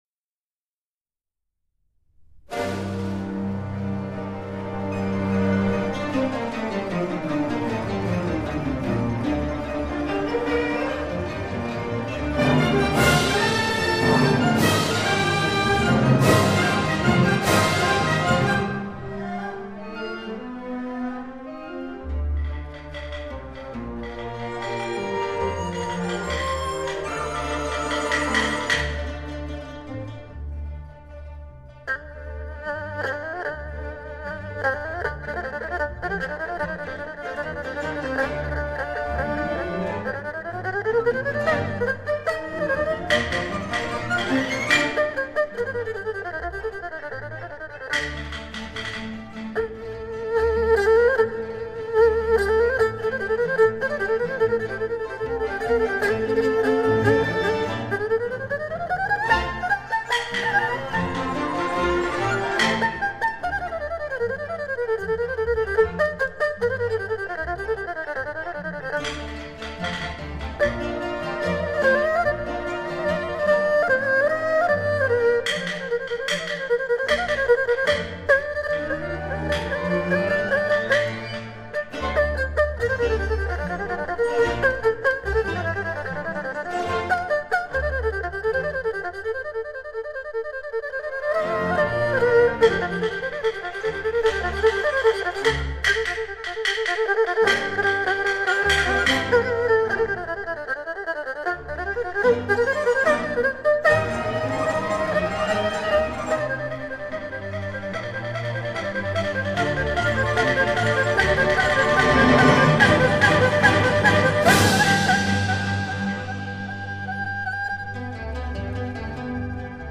二胡